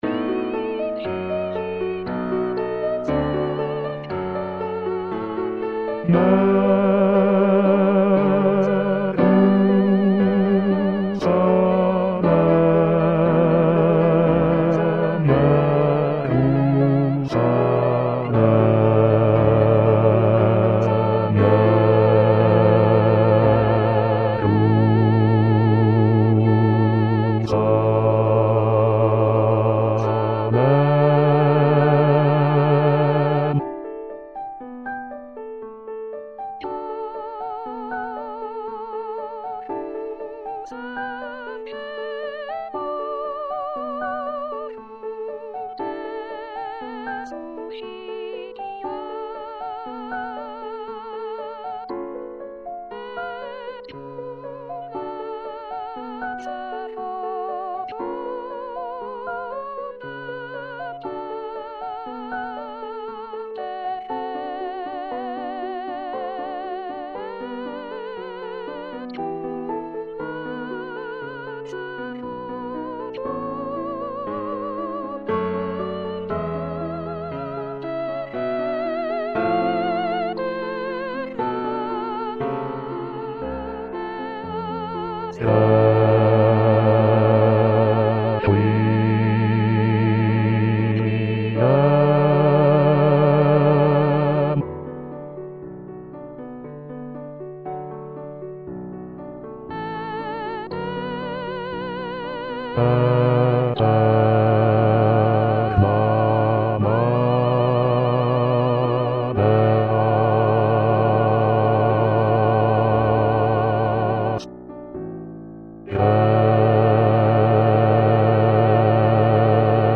ATTENTION : ces fichiers audio comportent peu ou pas de nuances, il ne s'agit (normalement!) que des bonnes notes à la bonne place
avec la bonne durée chantées par des voix synthétiques plus ou moins agréables .